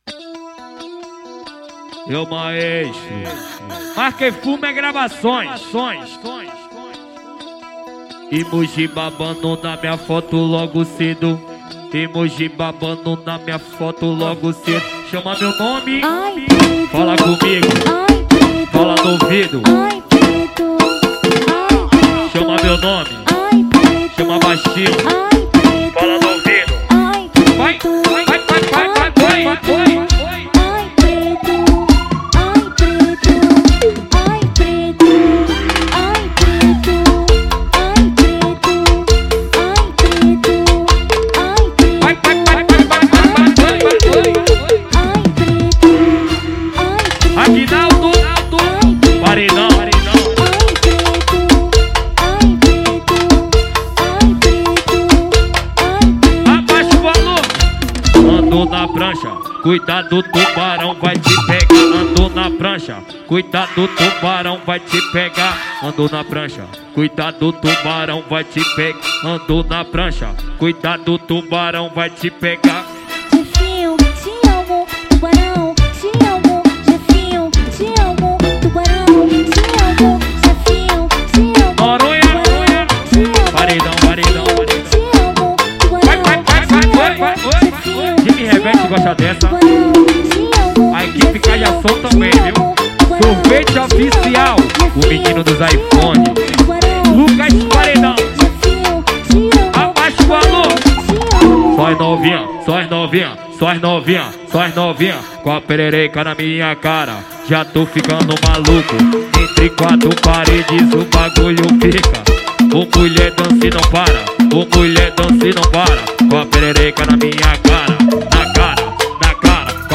2024-06-16 20:13:20 Gênero: MPB Views